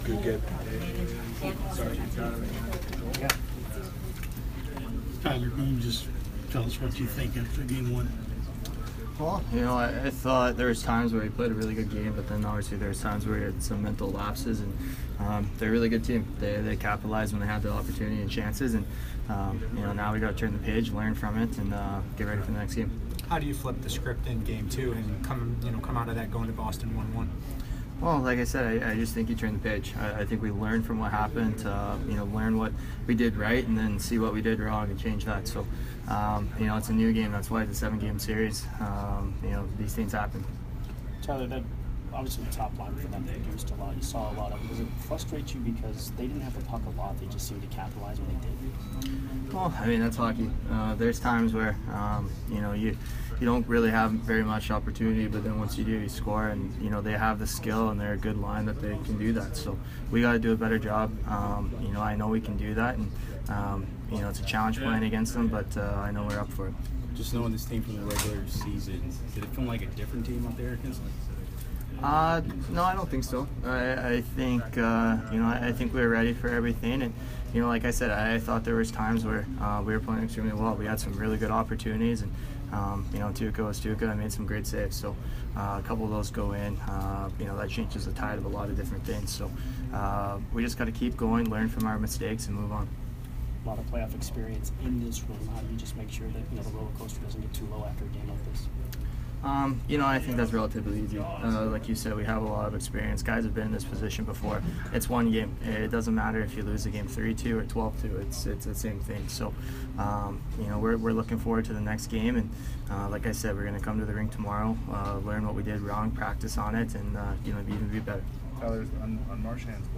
Tyler Johnson post-game 4/28